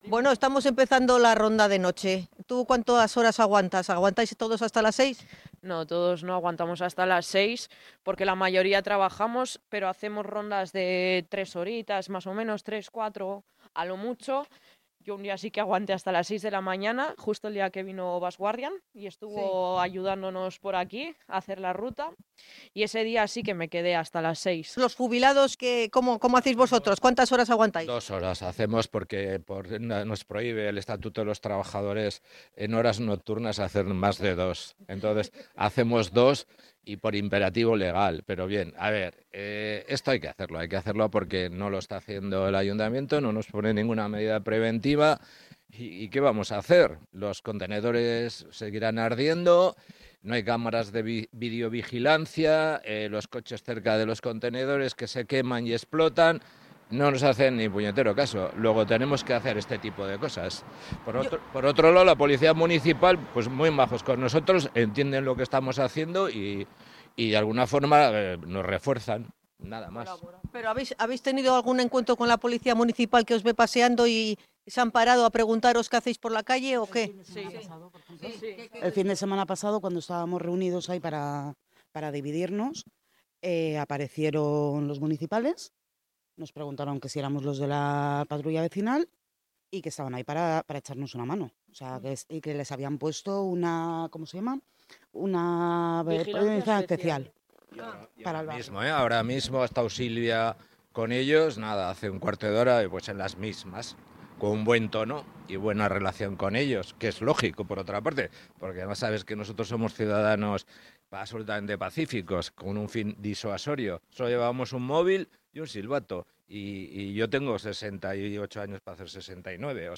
Ronda de noche: Acompañamos a los vecinos de Amezola en su patrulla de vigilancia